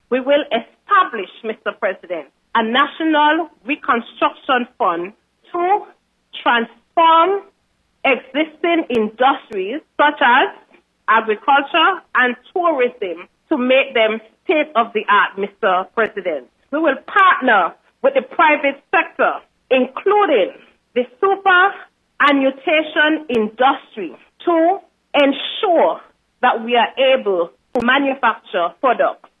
Lone member of the Opposition, the Hon. Cleone Stapleton-Simmonds, made her input during the Sitting of the Nevis Island Assembly after the tabling of the Draft Estimates 2022.
Here is an excerpt of her address: